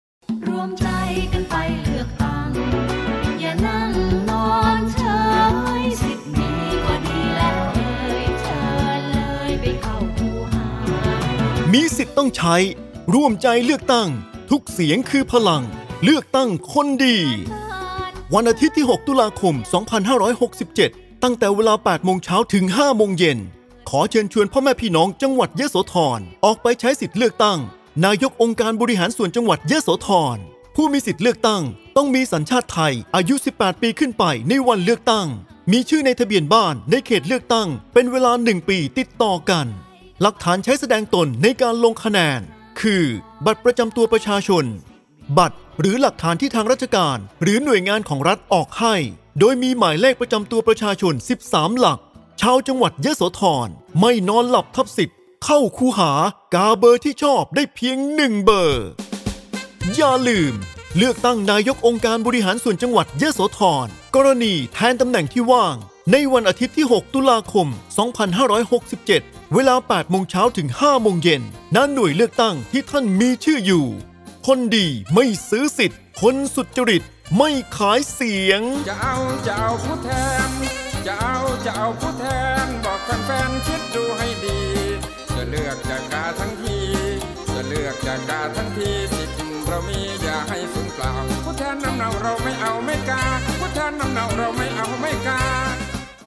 สปอตประชาสัมพันธ์เชิญชวนไปใช้สิทธิเลือกตั้งนายกองค์การบริหารส่วนจังหวัดยโสธร